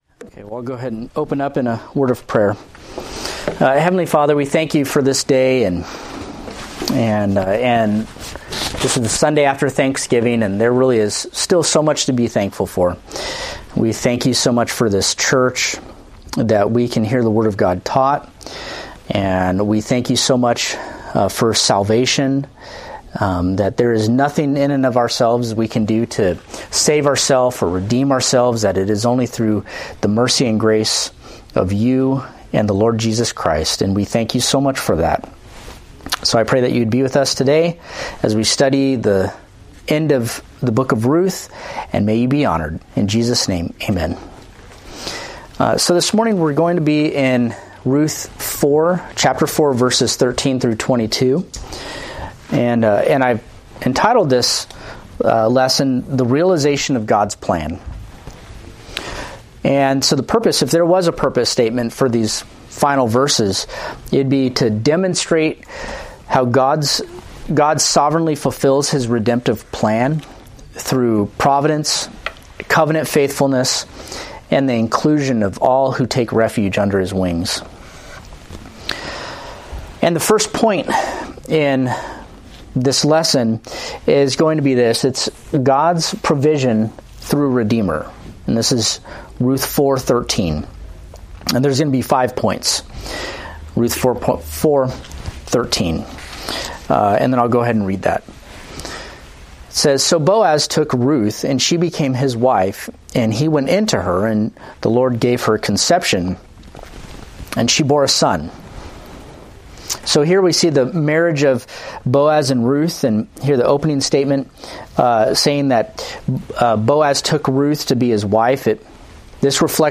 Date: Dec 1, 2024 Series: Ruth Grouping: Sunday School (Adult) More: Download MP3